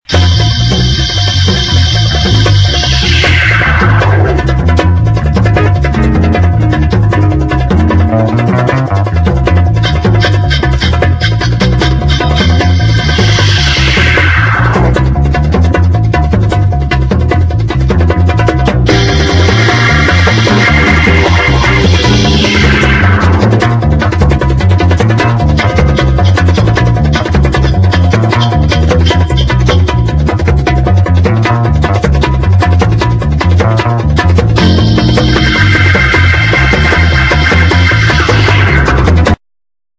sexy medium instr.